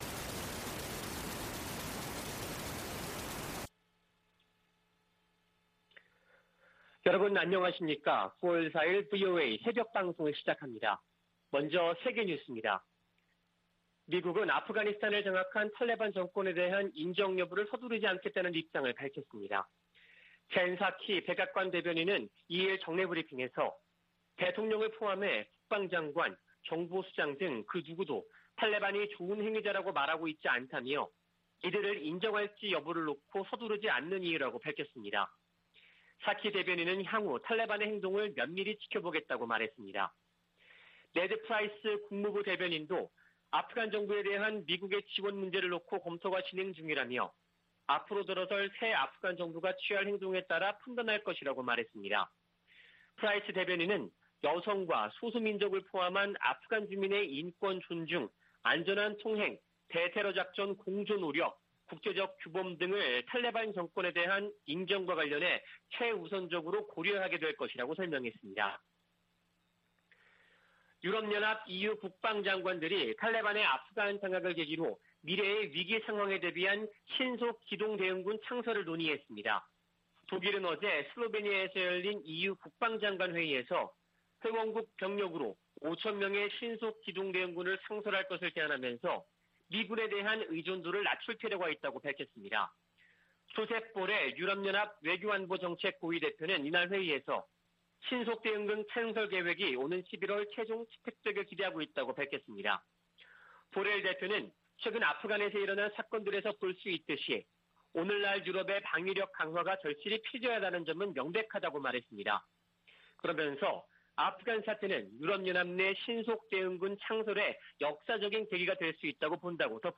VOA 한국어 '출발 뉴스 쇼', 2021년 9월 4일 방송입니다. 북한이 열병식을 준비하는 것으로 추정되는 모습이 포착됐습니다. 미국의 전직 관리들은 국무부의 북한 여행금지 연장 조치를 지지한다고 밝혔습니다. 주한미군 규모를 현 수준으로 유지해야 한다는 내용이 포함된 미국의 2022회계연도 국방수권법안이 하원 군사위원회에서 의결됐습니다.